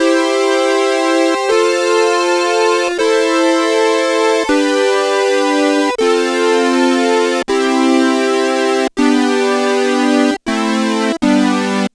Track 16 - Big Synth.wav